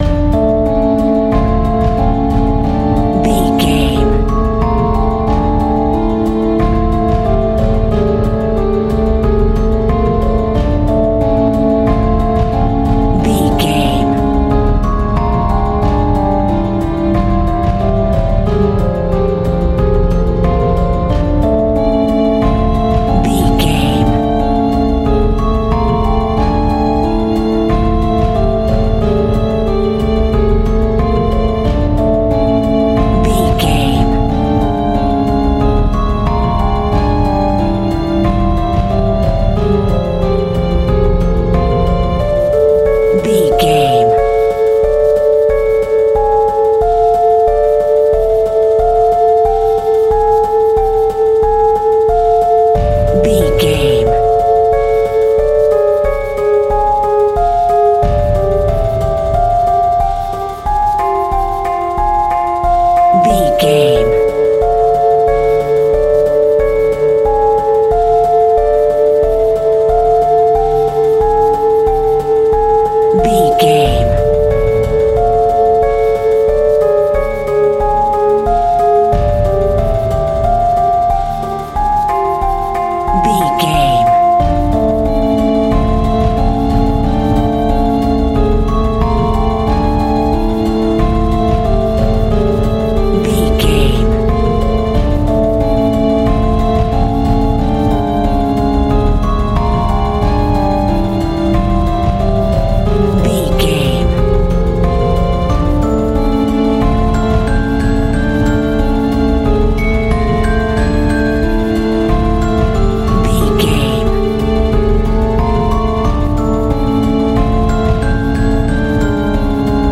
Aeolian/Minor
tension
ominous
dark
haunting
eerie
strings
electric piano
drums
percussion
synth
pads